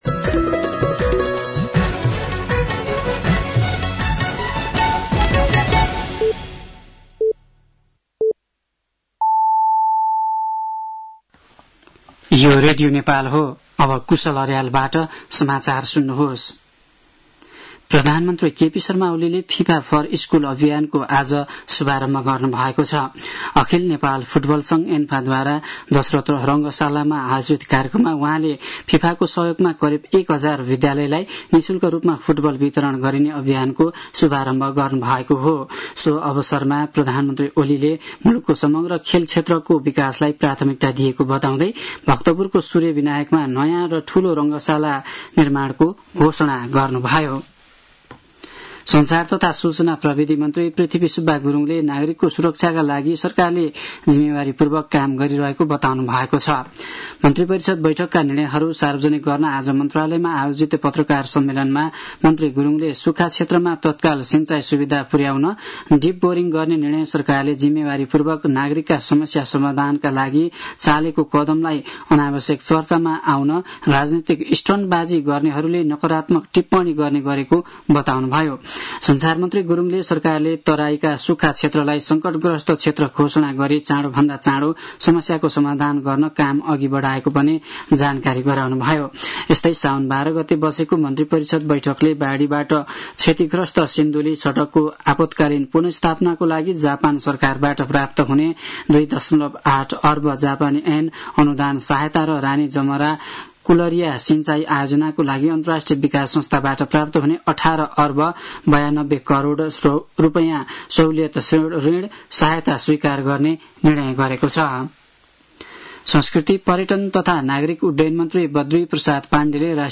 साँझ ५ बजेको नेपाली समाचार : १५ साउन , २०८२
5.-pm-nepali-news-1-7.mp3